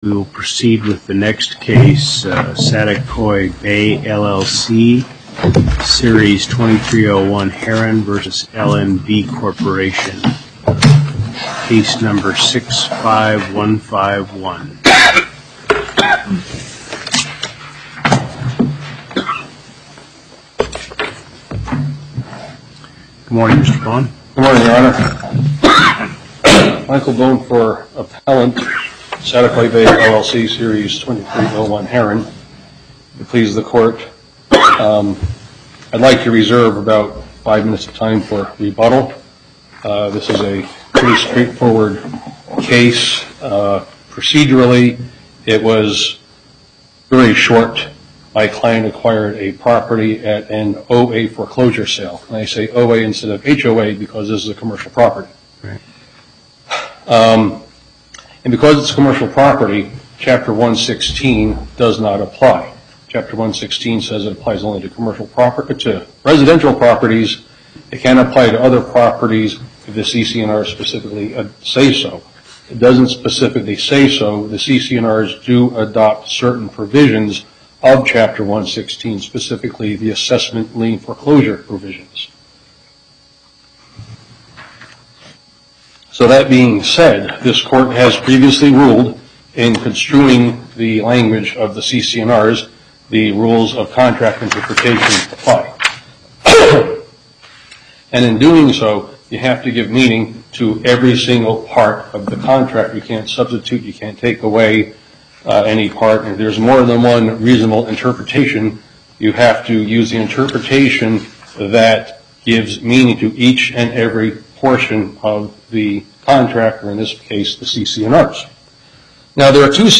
Location: Las Vegas Before the En Banc Court, Justice Hardesty presiding